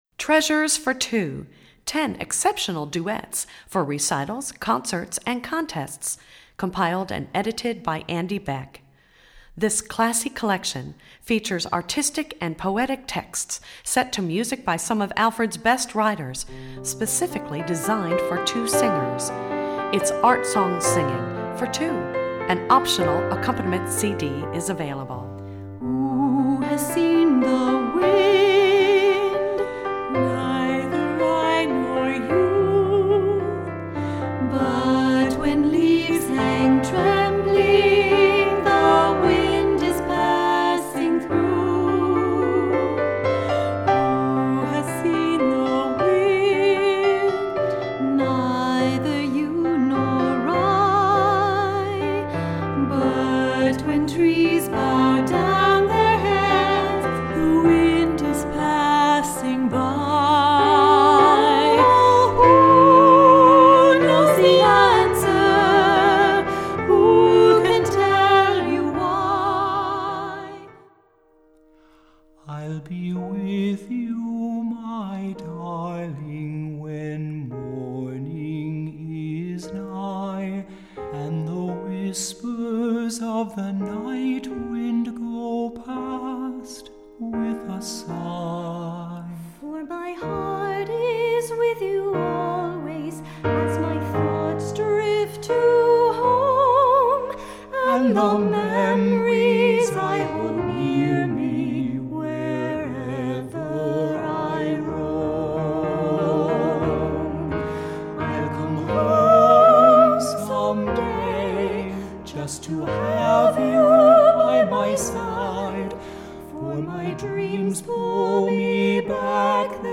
Voicing: Vocal Duet Book w/Online Audio